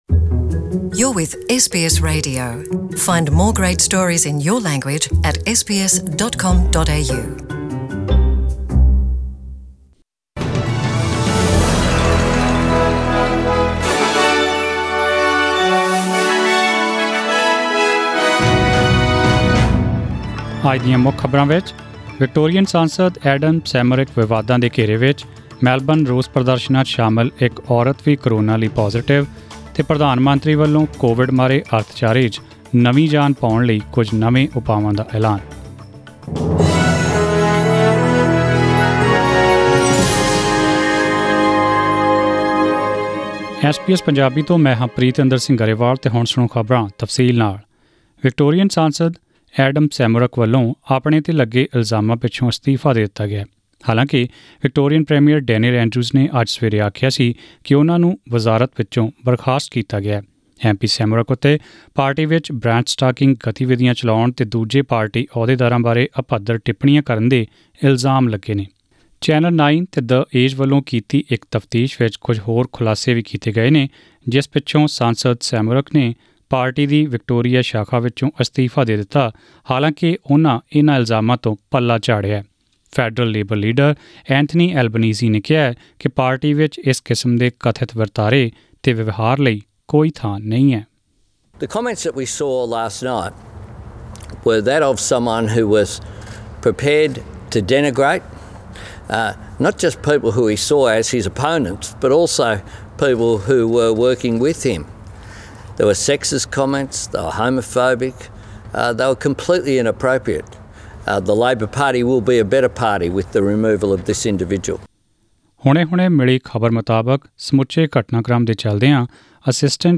To hear the full news bulletin, click on the audio player above.